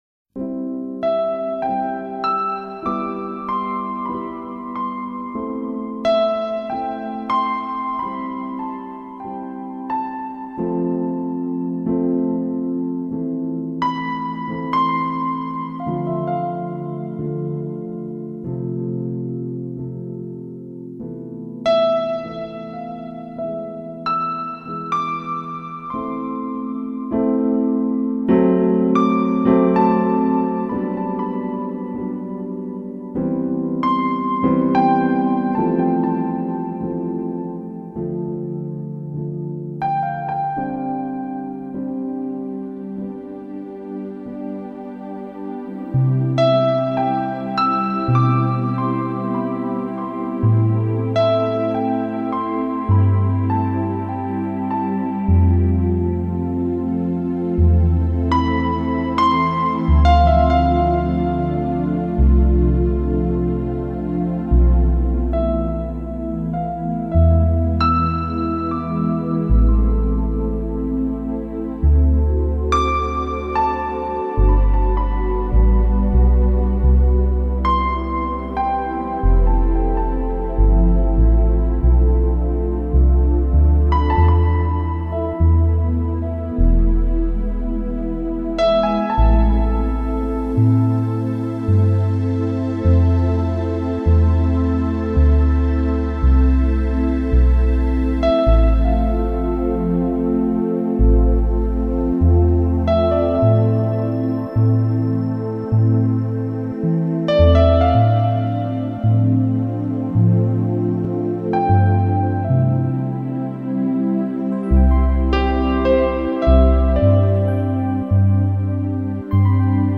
新世纪 钢琴